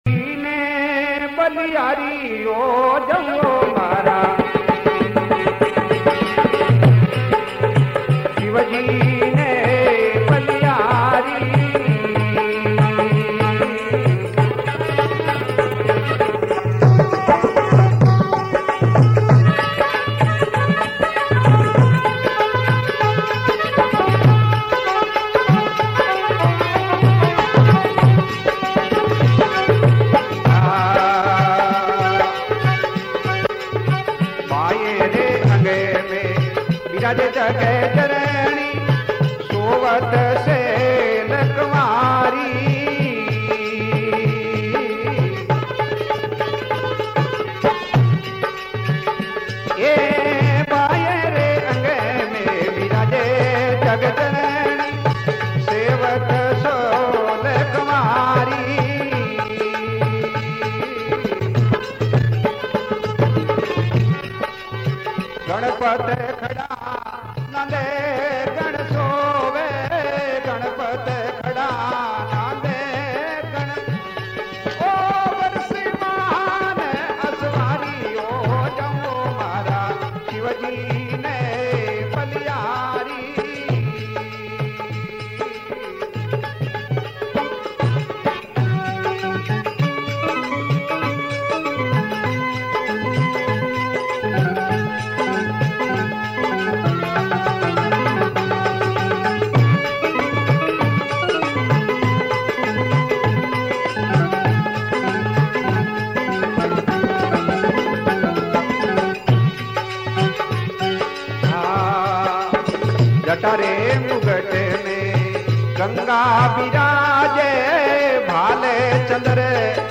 Rajasthani Songs
Satsang Bhajan